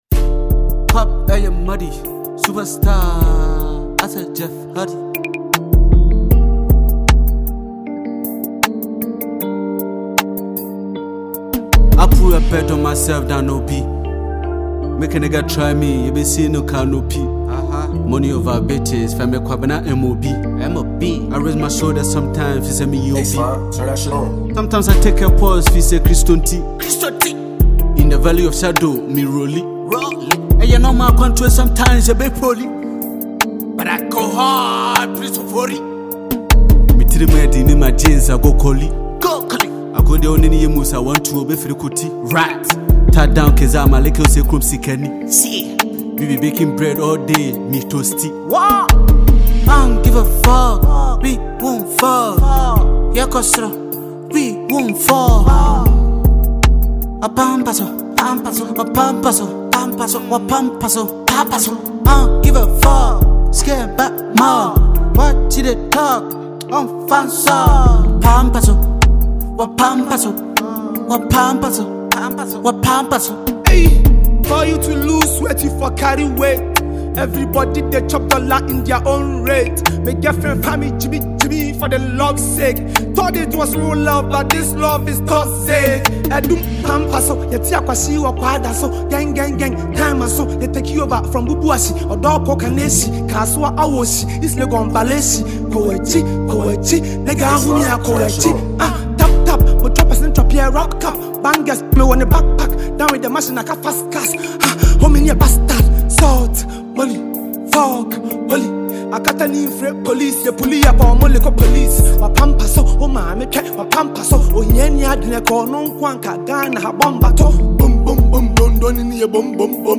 high-energy hip-hop/rap track
Genre: Hip-Hop / Rap